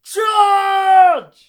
battle-cry-1.ogg